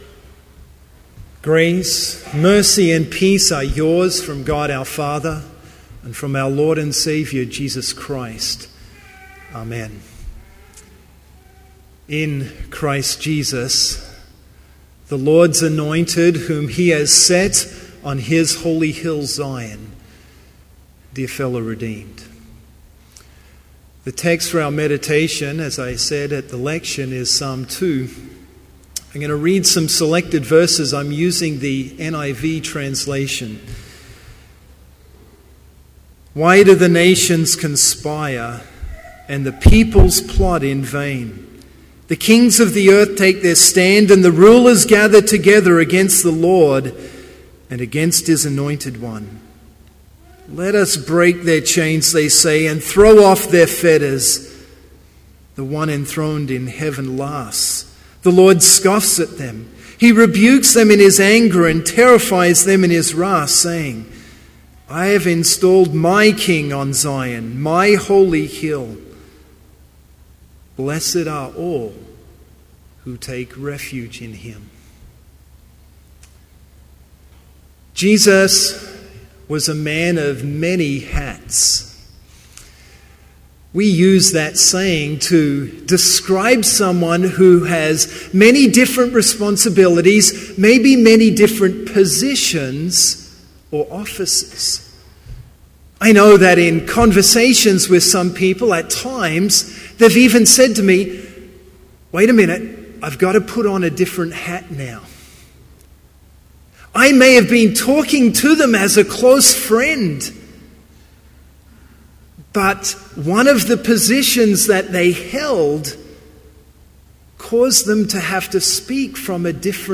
Complete service audio for Advent Vespers - December 11, 2013